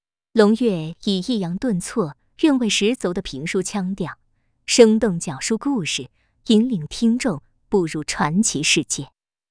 通過閱讀本文，您可以預覽各類型智能語音的效果。
CosyVoice基於新一代產生式語音大模型，能根據上下文預測情緒、語調、韻律等，具有更好的擬人效果。